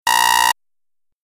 دانلود آهنگ هشدار 12 از افکت صوتی اشیاء
جلوه های صوتی